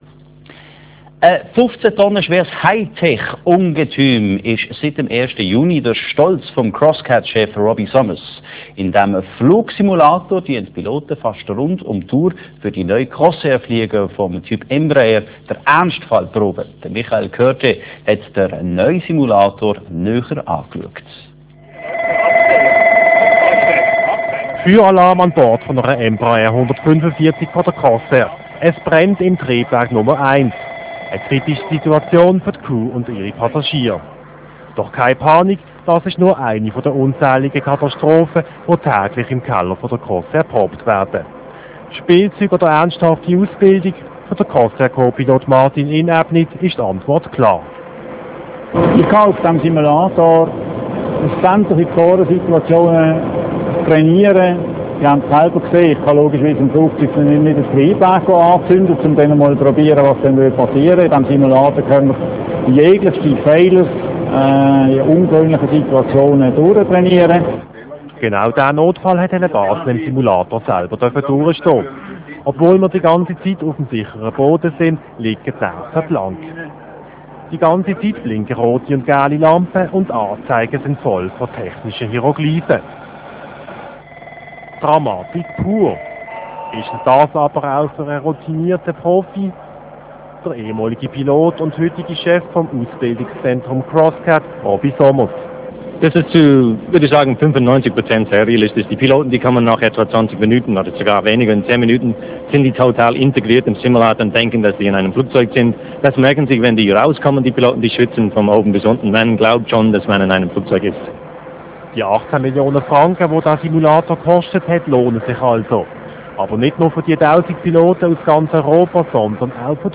Telebasel-Film über Crossairs Embraer-Simulator als Download (Real Video)